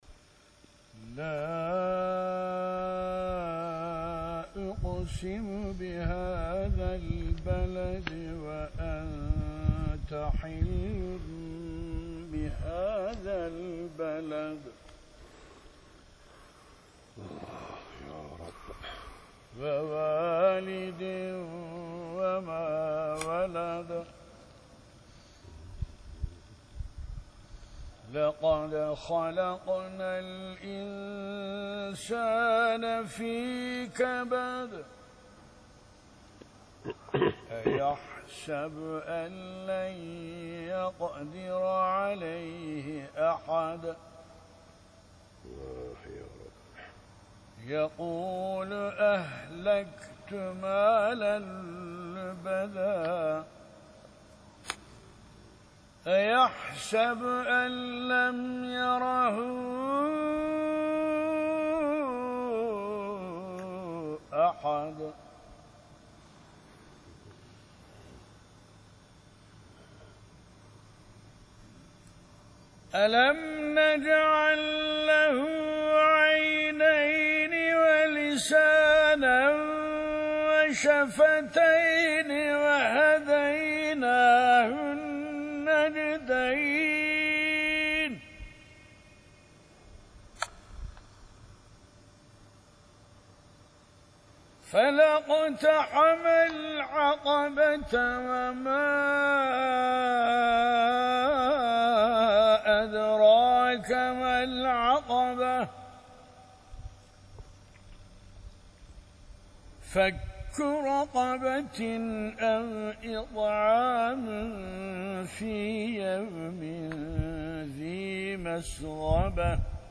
53 Beled 1-20, Beyyine 1-8, İhlas 1-4. Ayetler - 2005 - Yer:Akşehir